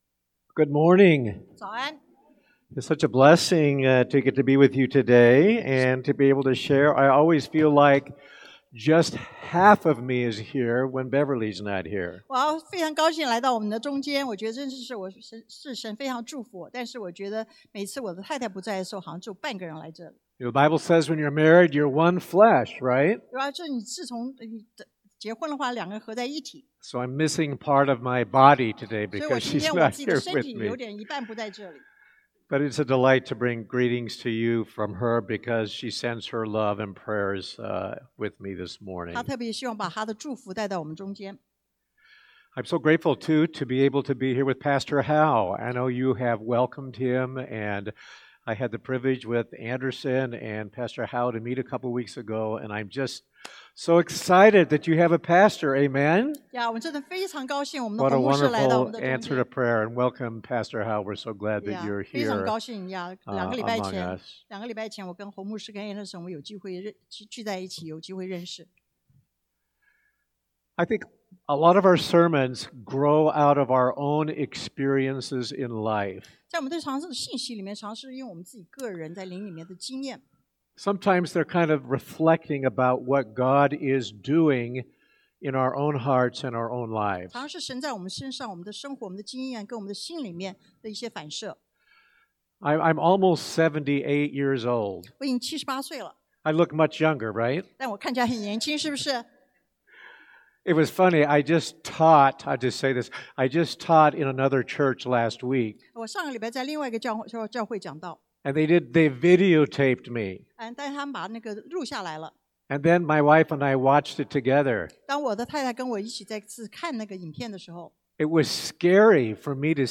Passage: 以弗所書 EPHESIANS 4:11-16 Service Type: Sunday AM The crises of not growing up 靈命不長進的危機